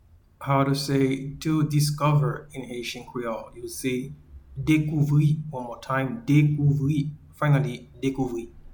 Pronunciation and Transcript:
to-Discover-in-Haitian-Creole-Dekouvri.mp3